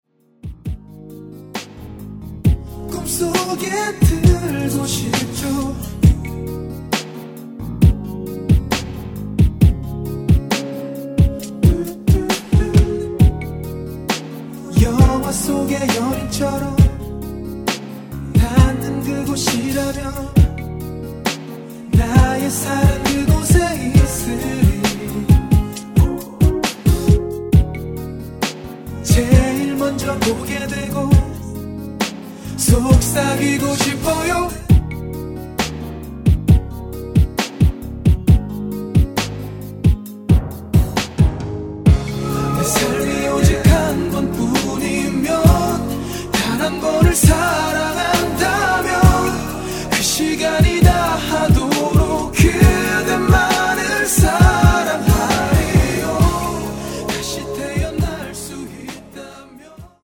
Db
앞부분30초, 뒷부분30초씩 편집해서 올려 드리고 있습니다.
중간에 음이 끈어지고 다시 나오는 이유는